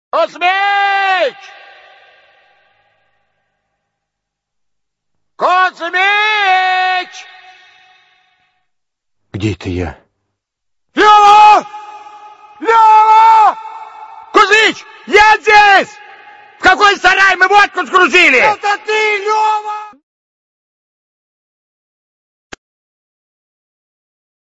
Отрывок диалога Кузмича с Левой про водку(из к/ф Особенности национальной рыбалки)